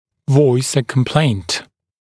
[vɔɪs ə kəm’pleɪnt][войс э кэм’плэйнт]высказать жалобу (напр. родитель за ребенка)